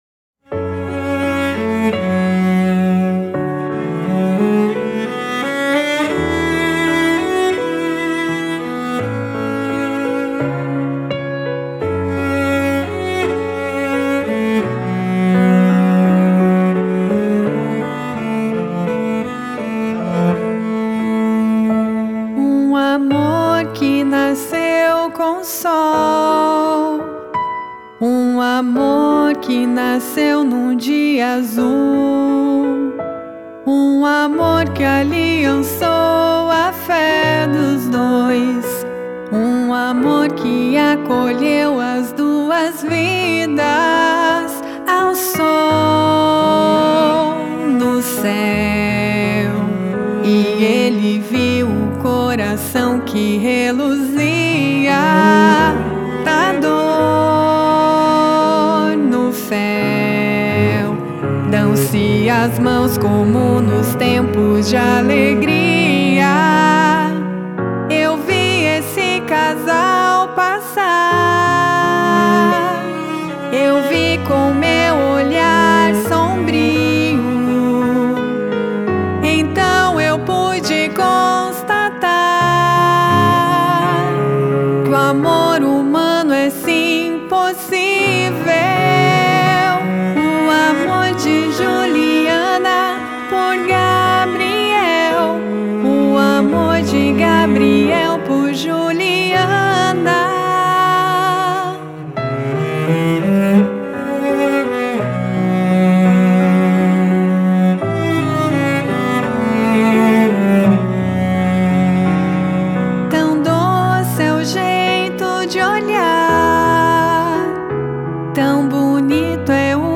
Piano
• Cello